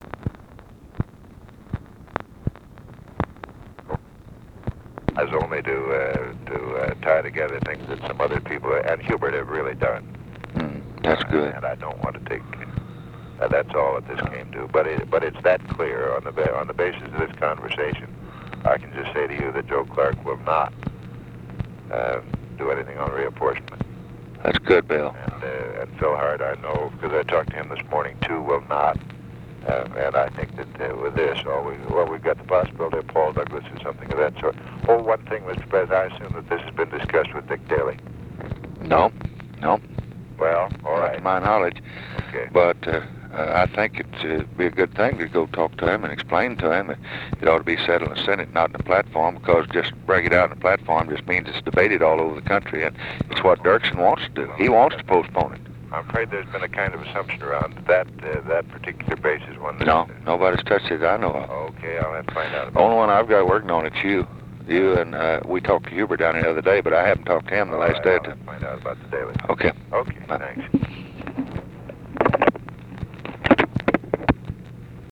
Conversation with (possibly) WILLARD WIRTZ, August 22, 1964
Secret White House Tapes